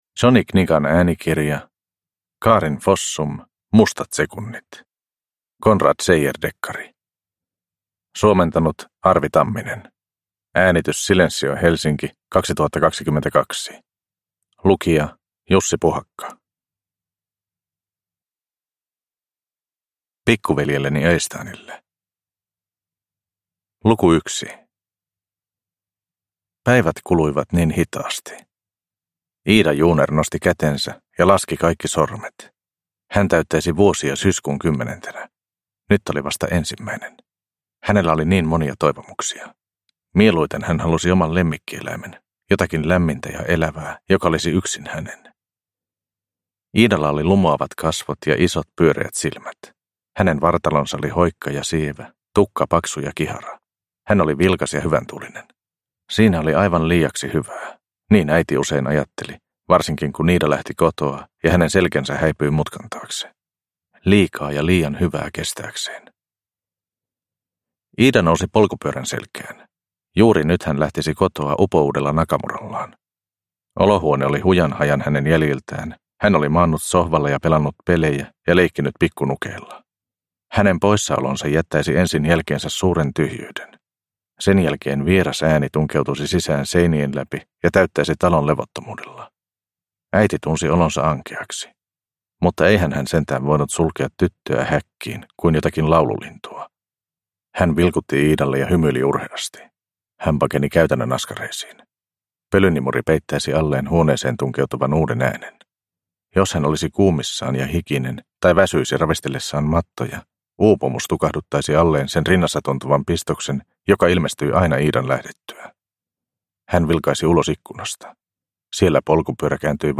Mustat sekunnit – Ljudbok – Laddas ner